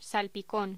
Locución: Salpicón
Sonidos: Voz humana